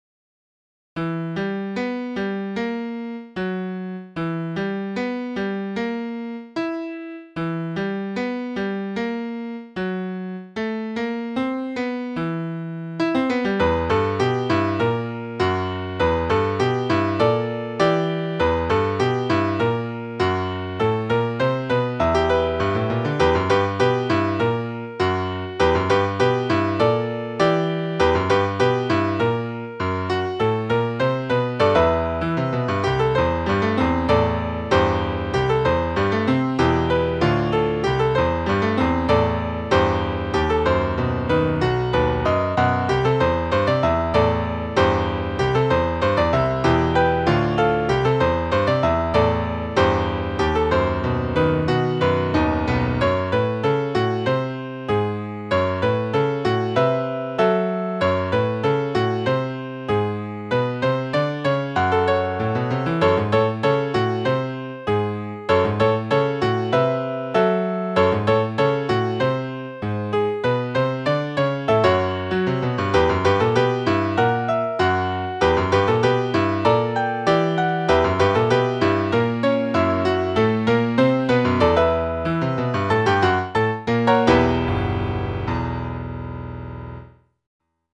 FUNERAL DOOM MUSIC